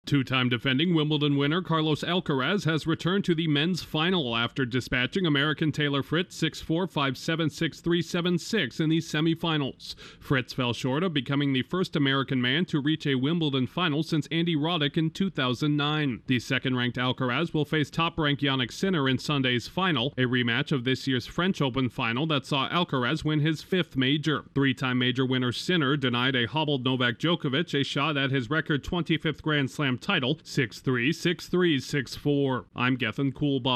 The top two players in men’s tennis are set to collide in a second straight major final. Correspondent